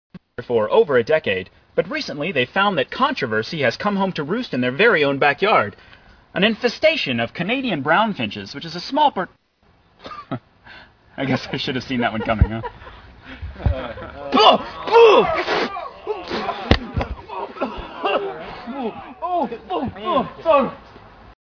Tags: News Anchor Fail News Anchor Fail clips News fail Reporter fail News fail clips